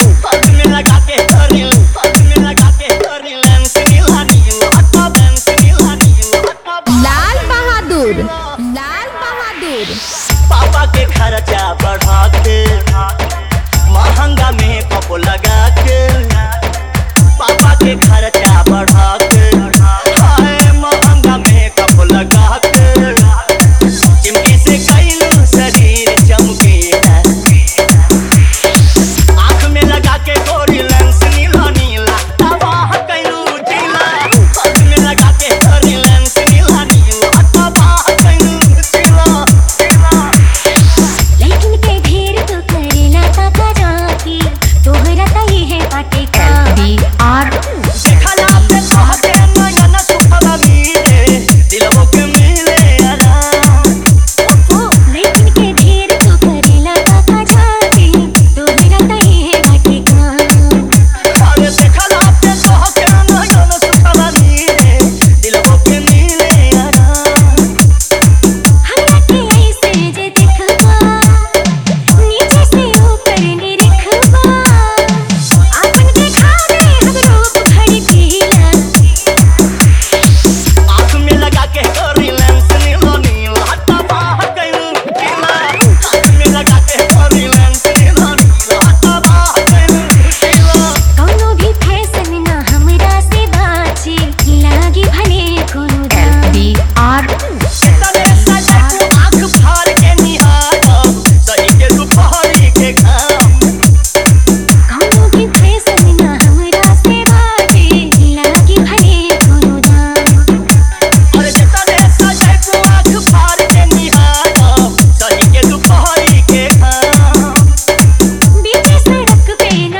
2025 Bhojpuri DJ Remix - Mp3 Songs